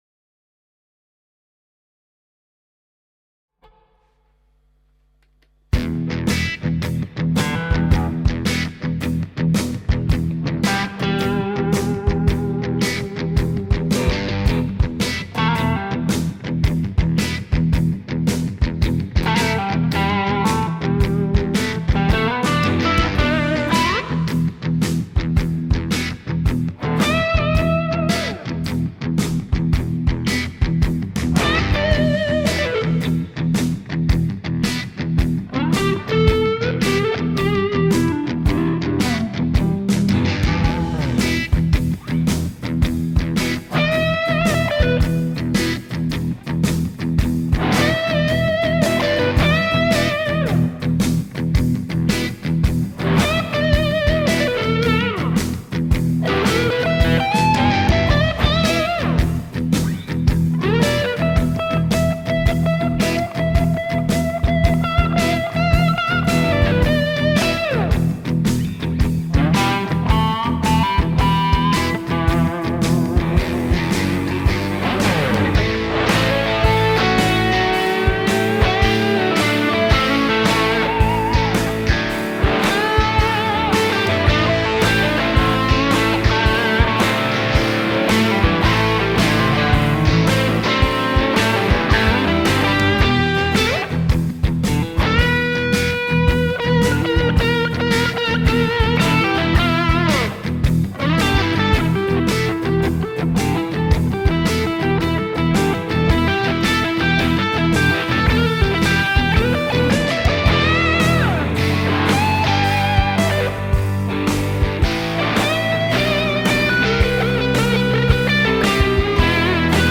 Lockdown-Blues---Blues-shuffle-jam-2-in-E---Fender-68-reissue-and-Blackstar-HT-Club-40-Deluxe-160k.mp3